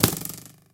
bowhit1.ogg